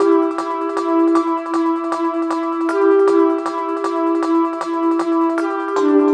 Track 11 - Tone Bells 01.wav